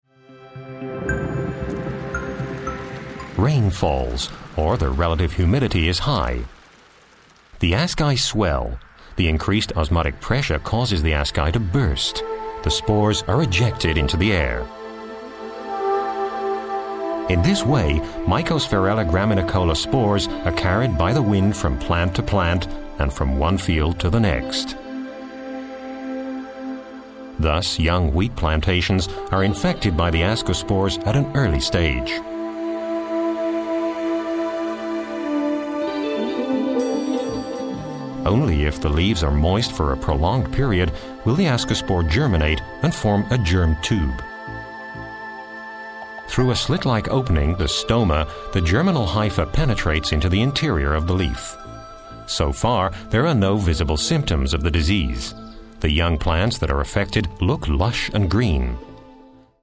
Deutsch spricht er mit leichtem englischem Akzent.
Sprecher englisch uk.
Sprechprobe: Industrie (Muttersprache):
voice over artist english (uk)